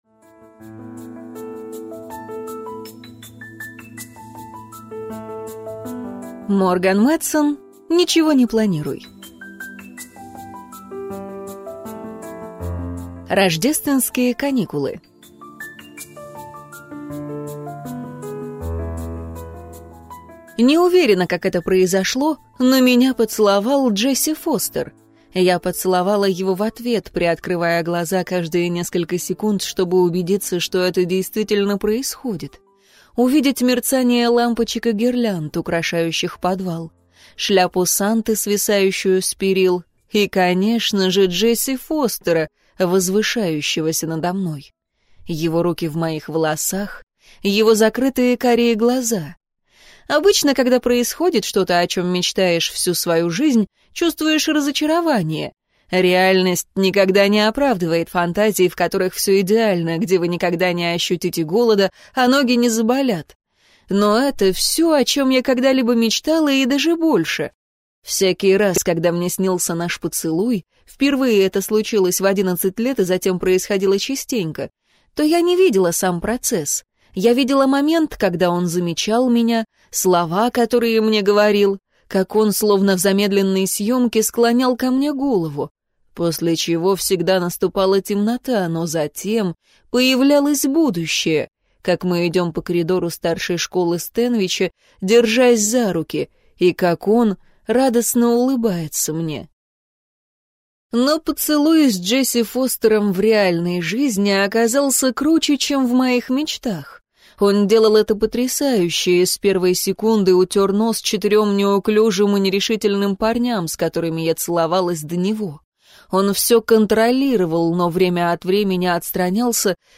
Аудиокнига Ничего не планируй | Библиотека аудиокниг